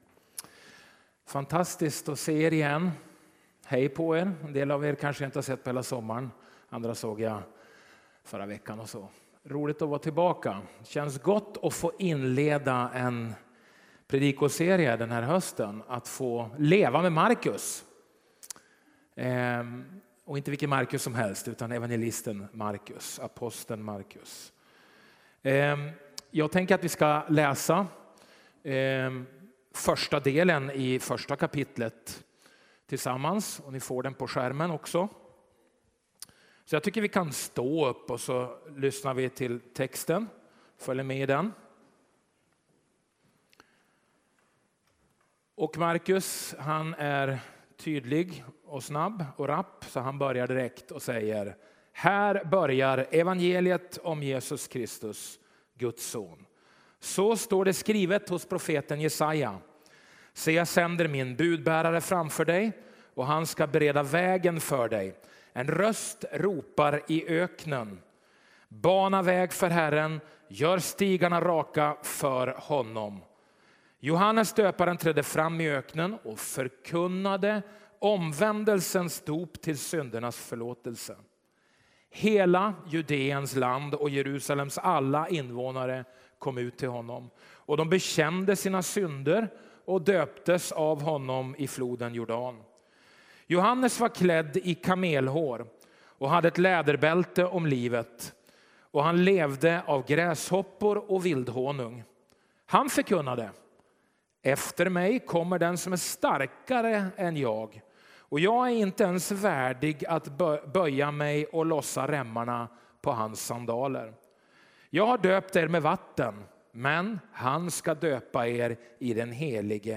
Predikningar